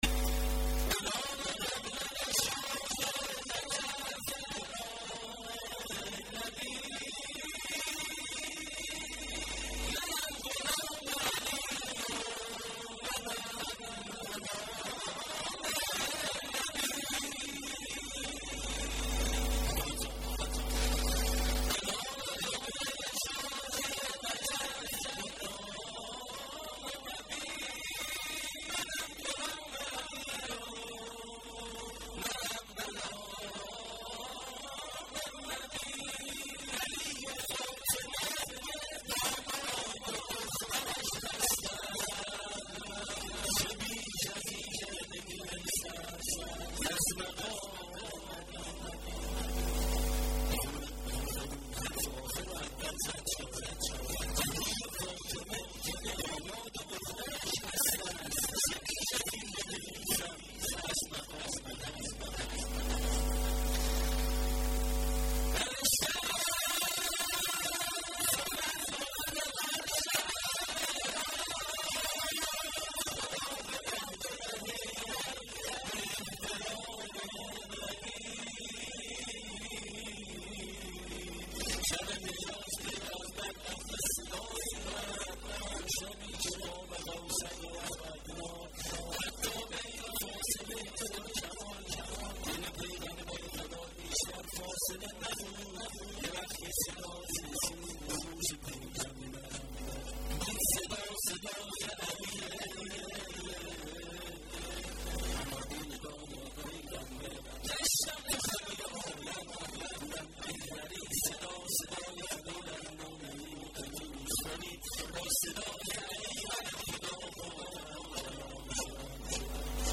ولادت حضرت محمد (ص) و امام صادق(ع)۴آذر۹۷